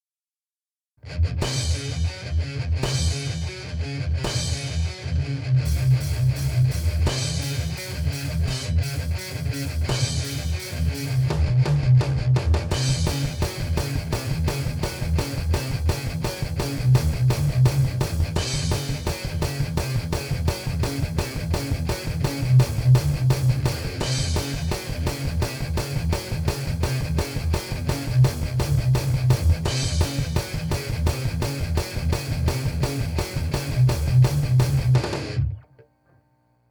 When I said, "the overheads were used," I meant that I mixed the close mic and the overheads, though they technically aren't overheads.